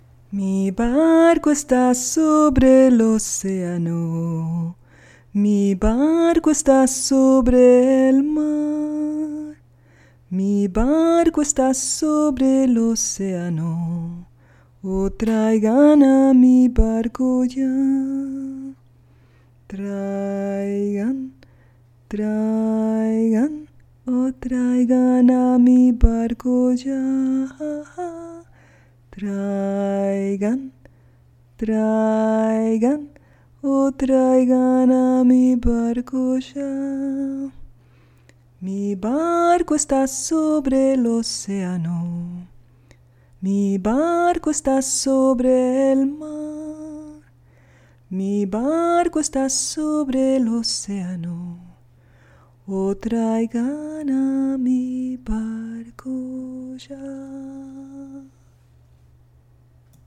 Una pequeña canción que me inventé para jugar en el agua con los niños…  Una melodía que es muy reconfortante para ti y para que tu niño se duerma en poco tiempo cuando lo cantes una y otra vez.